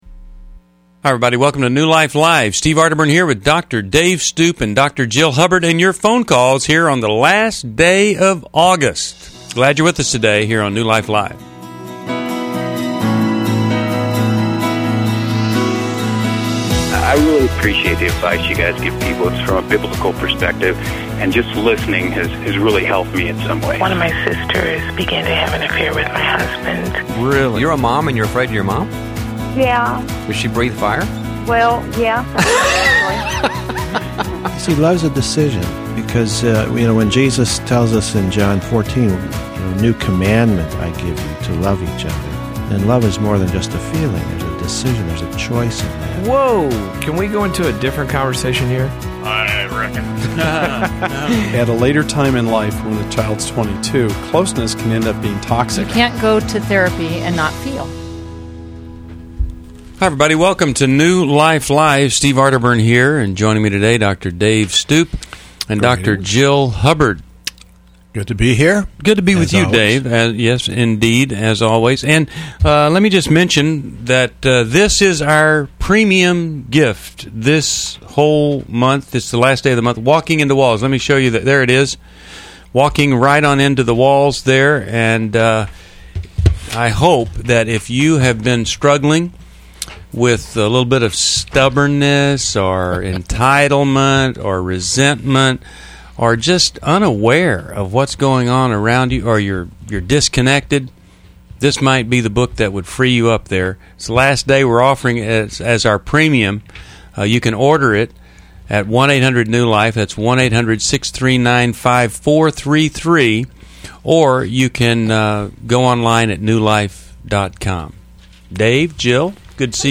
Caller Questions: 1. How do I confront my ex-fiance about his debts?